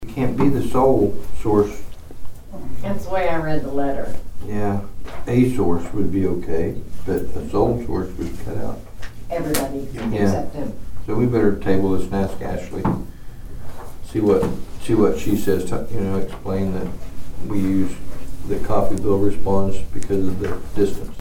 The Nowata County Commissioners held a regularly scheduled meeting at the Nowata County Annex Monday morning.
District Three Commissioner Troy Friddle discussed why Nowata EMS can't be the sole source.